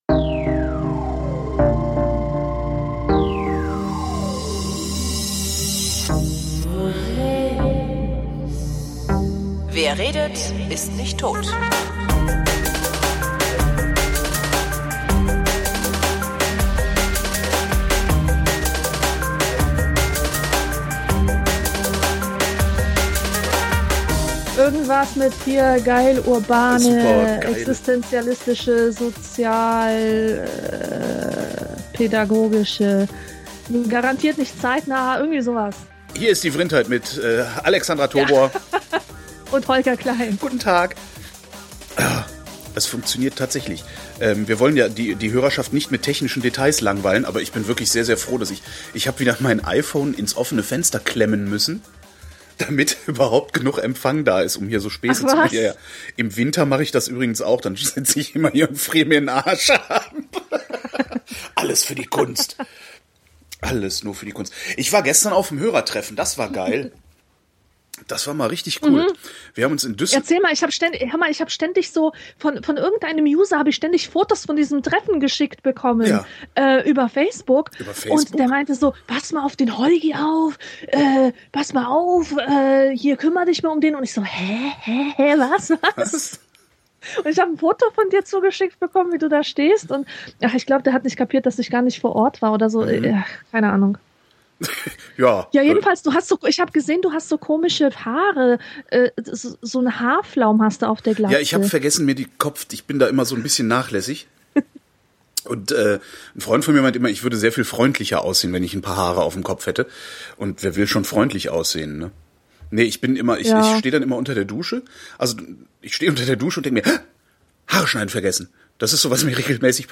Heute aus der Quasi-Edgehölle, die nach 80 Minuten die Verbindung verweigert hat, so dass die Sendung entsprechend kurz ist